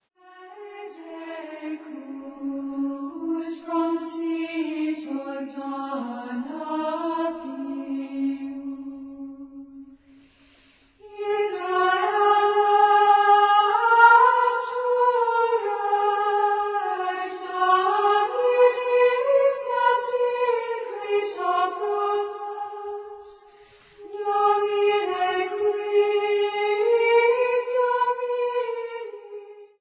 A ce titre, il composa l'hymne "Gloria Laus", qui est toujours chanté à Orléans et Germigny-des-Prés, lors de la procession des Rameaux.